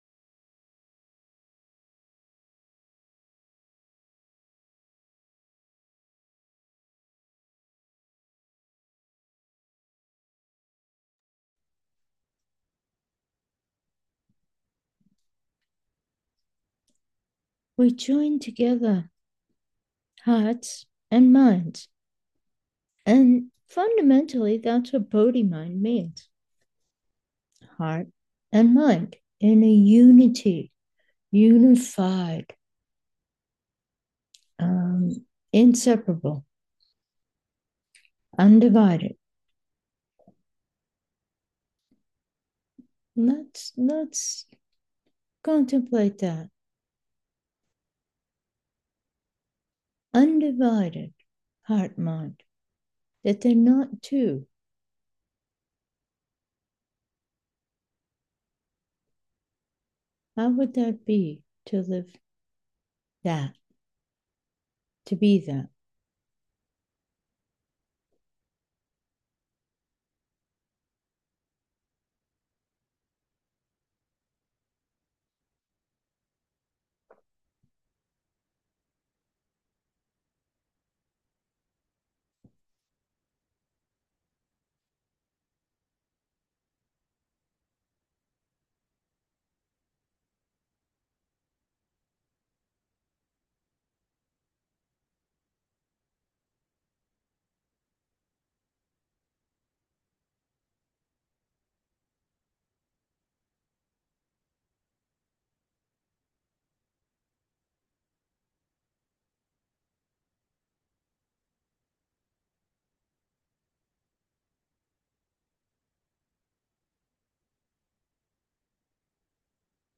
Meditation: unity, unified